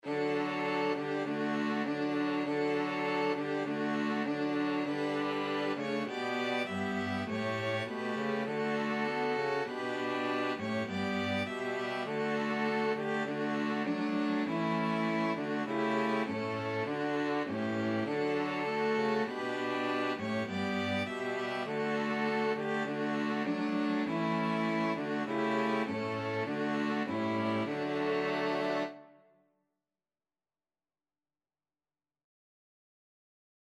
Violin 1Violin 2ViolaCello
4/4 (View more 4/4 Music)
D major (Sounding Pitch) (View more D major Music for String Quartet )
String Quartet  (View more Easy String Quartet Music)
Classical (View more Classical String Quartet Music)
armenia_nat_STRQ.mp3